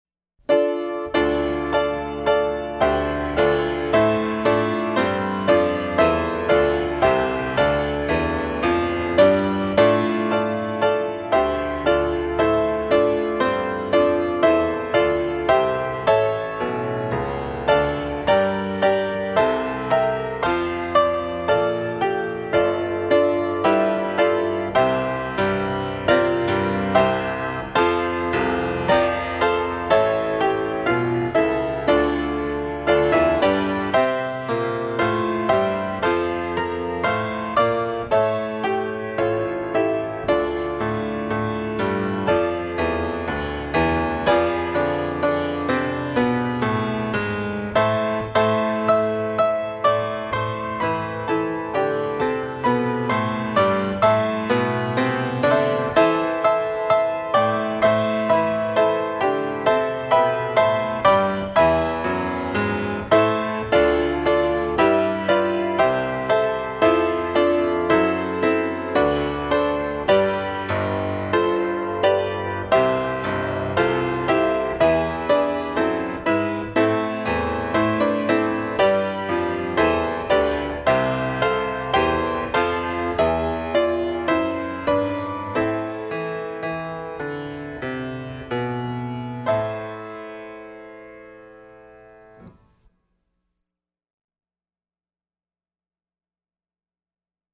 DIGITAL SHEET MUSIC - PIANO SOLO
Traditional English Carol, Piano Solo